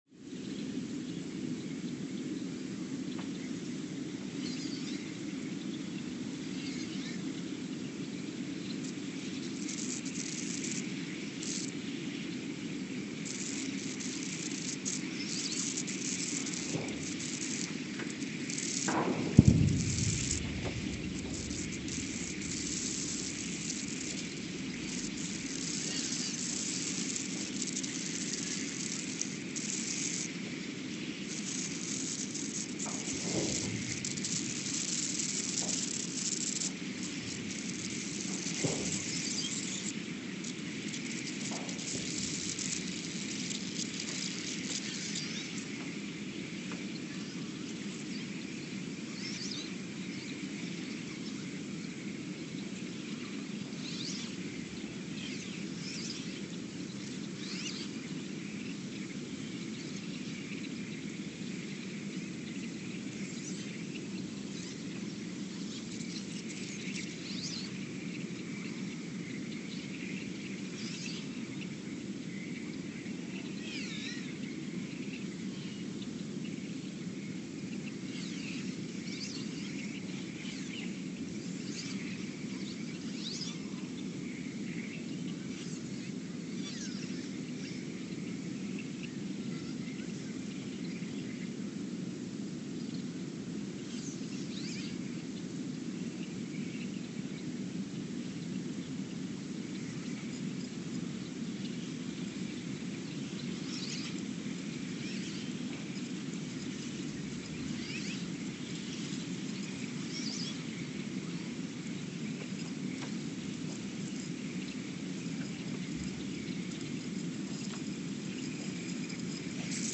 The Earthsound Project: Ulaanbaatar, Mongolia (seismic) archived on August 30, 2023
Sensor : STS-1V/VBB
Recorder : Quanterra QX80 @ 20 Hz
Speedup : ×900 (transposed up about 10 octaves)
Loop duration (audio) : 03:12 (stereo)
SoX post-processing : highpass -2 90 highpass -2 90